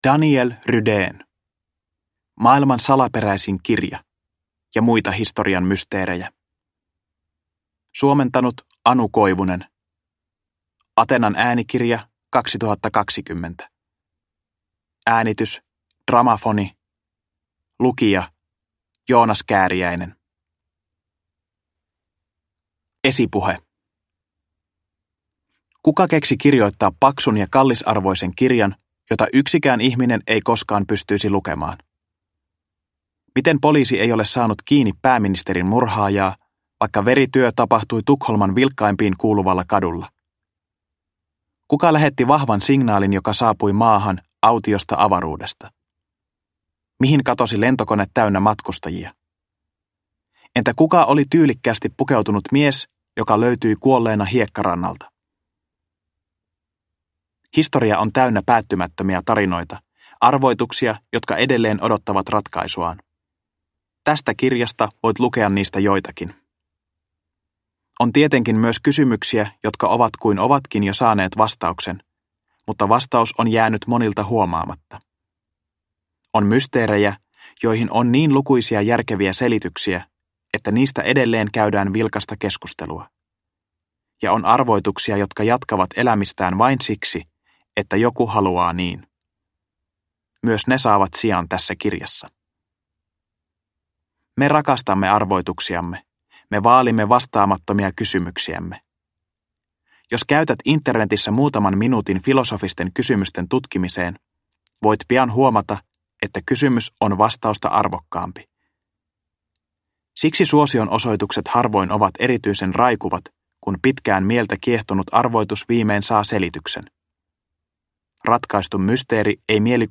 Maailman salaperäisin kirja - ja muita historian mysteerejä – Ljudbok – Laddas ner